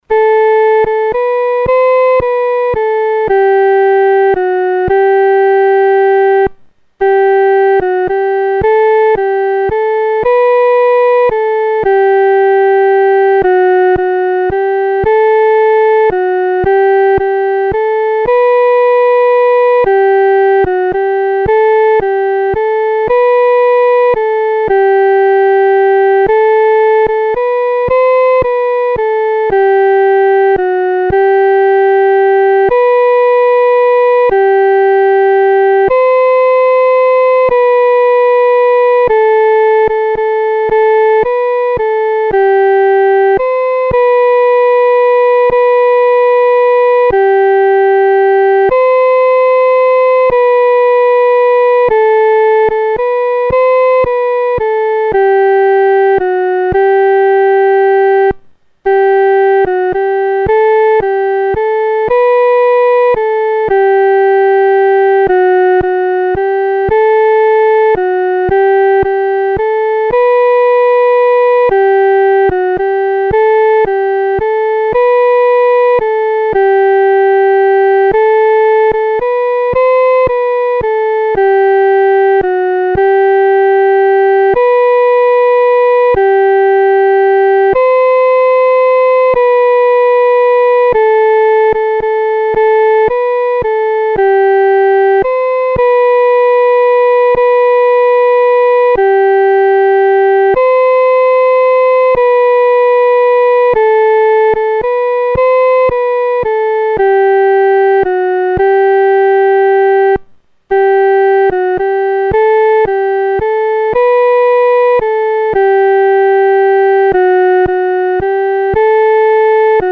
独奏（第一声）